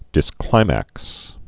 (dĭs-klīmăks)